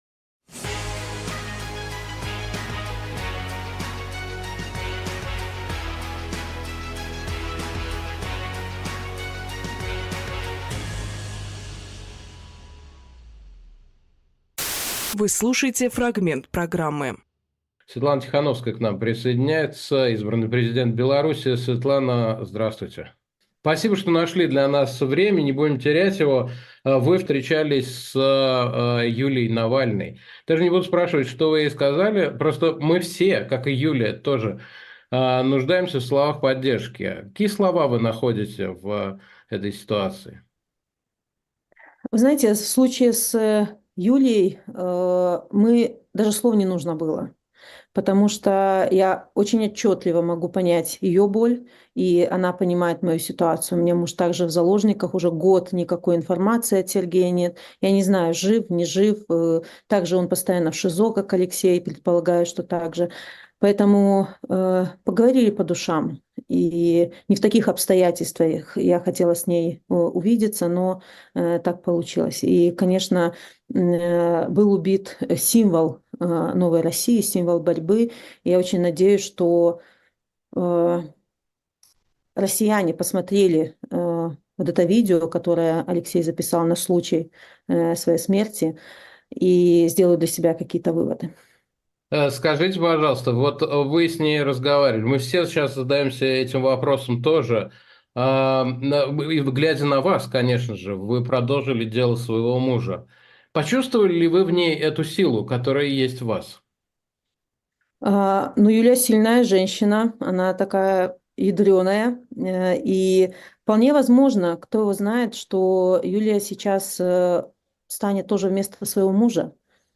Александр Плющевжурналист
Светлана ТихановскаяЛидер оппозиции Беларуси
Фрагмент эфира от 17.02.2024